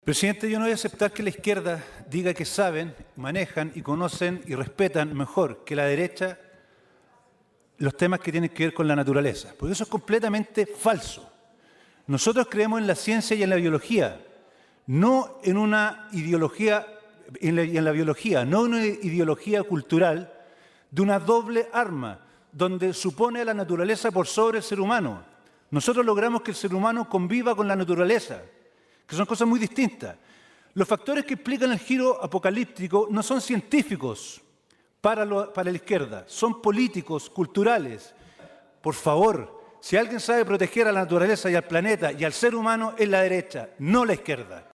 También representando al Distrito 21, el diputado Cristóbal Urruticoechea, del Partido Nacional Libertario, manifestó que “si alguien sabe proteger a la naturaleza, al planeta y al ser humano es la derecha, no la izquierda”.